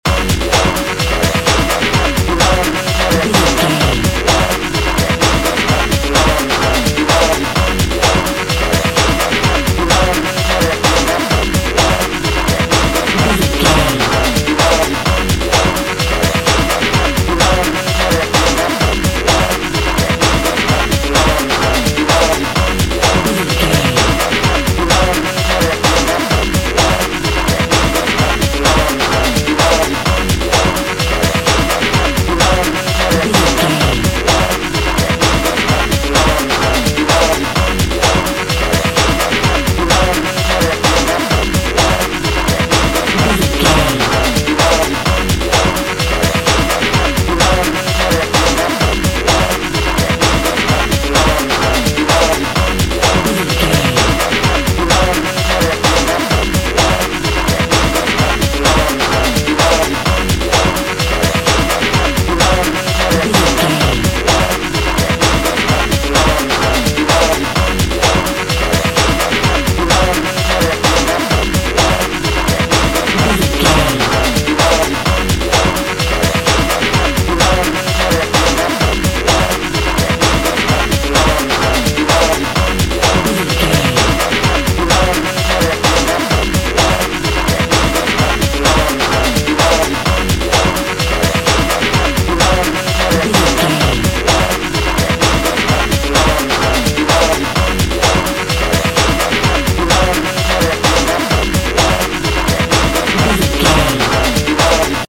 Epic / Action
Fast paced
Aeolian/Minor
F#
aggressive
dark
driving
energetic
intense
drum machine
synthesiser
breakbeat
power rock
synth leads
synth bass